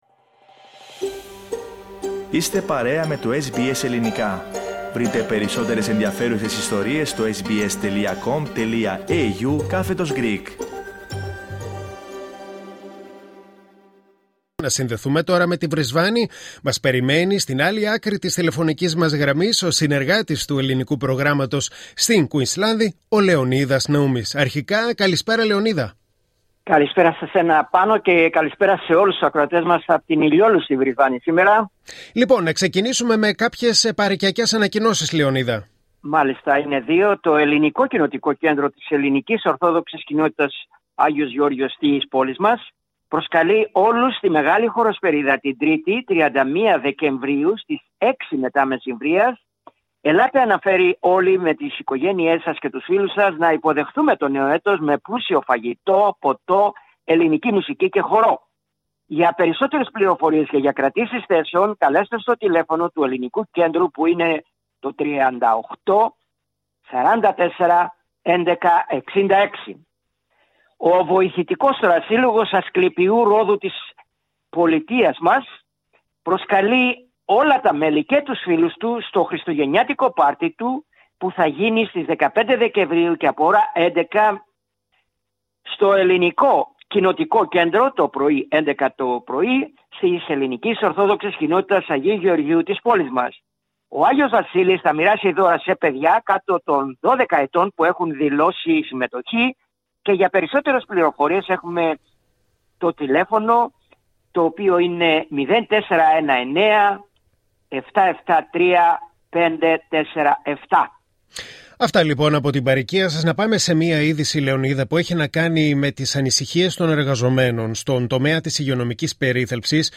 Ακούστε ολόκληρη την ανταπόκριση από την Βρισβάνη πατώντας PLAY πάνω από την κεντρική εικόνα.